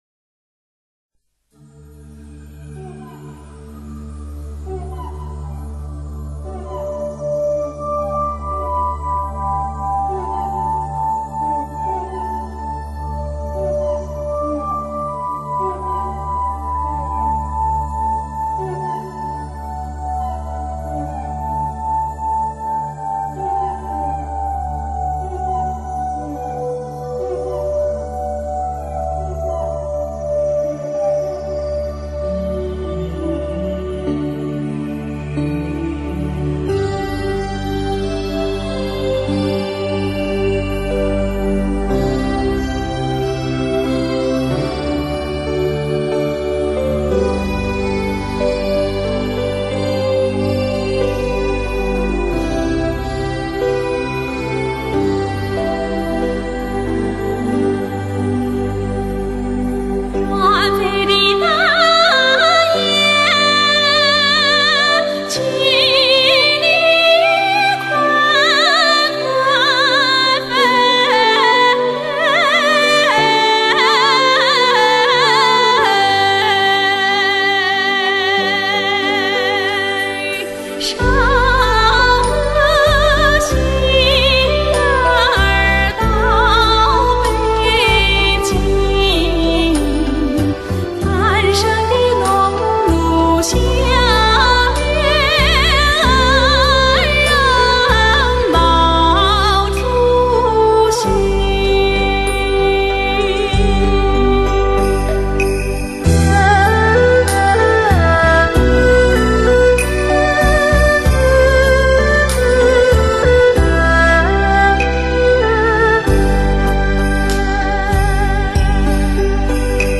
2007冠军之作，雅鲁藏布江的水，青藏高原的风，广裹与沉蕴，奔放与豪迈。